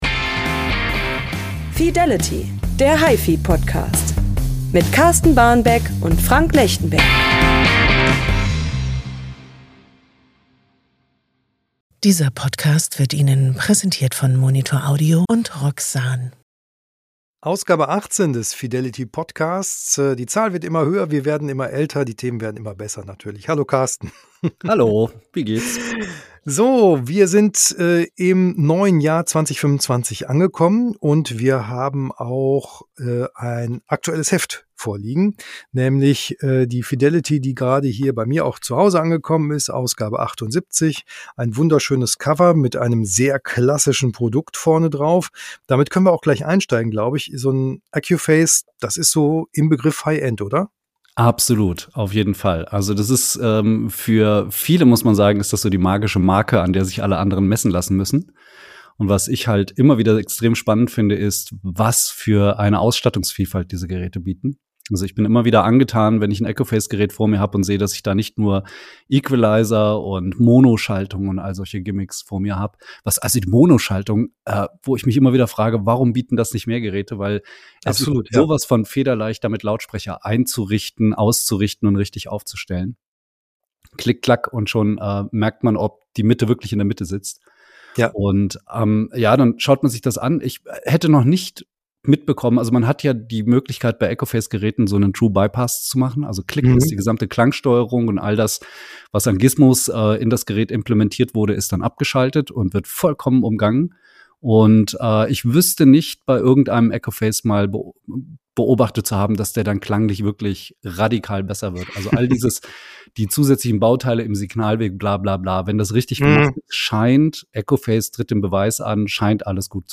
Geräte, Gerüchte und gute Musik! Der gepflegte Talk am Kaffeetisch zu unserem liebsten Hobby.